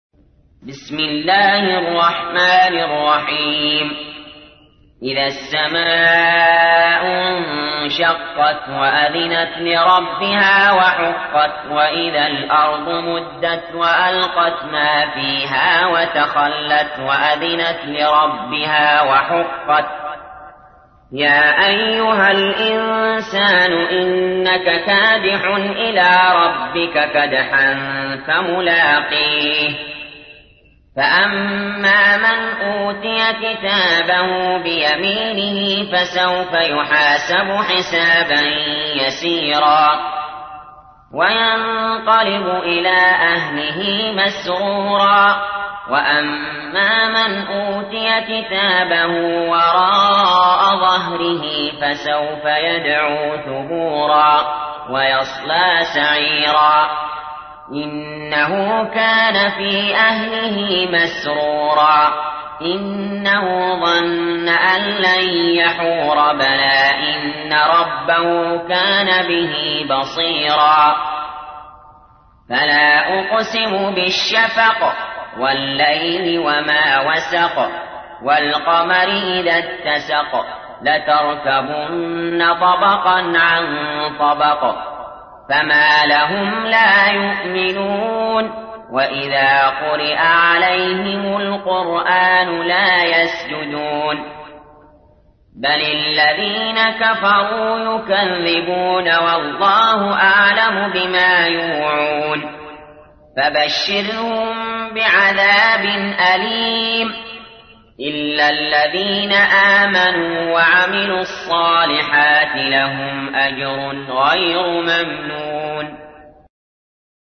تحميل : 84. سورة الانشقاق / القارئ علي جابر / القرآن الكريم / موقع يا حسين